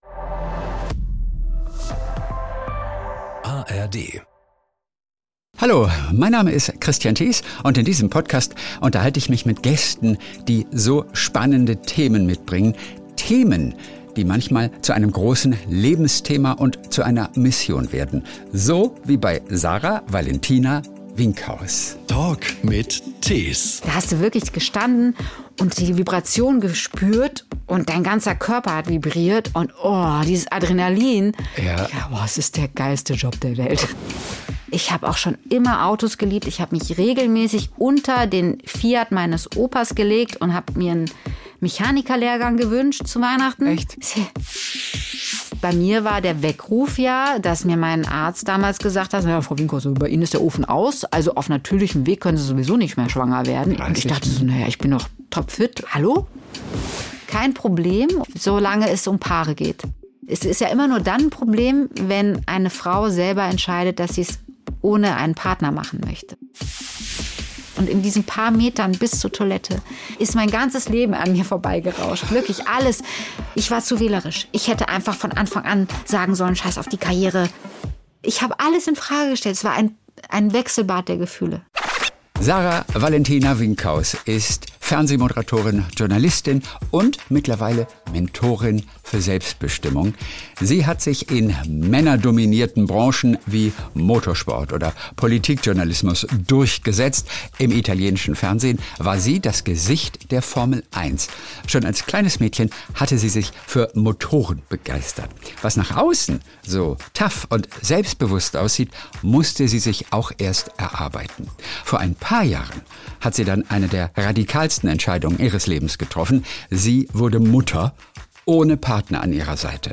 Hier erfahrt ihr, warum Frauen zu oft auf den perfekten Moment warten und warum ihnen dabei die Zeit schon relativ früh davonläuft. Ein wunderbar lebendiges und offenes Gespräch – auch über die Unterschiede zwischen Italienern und Deutschen.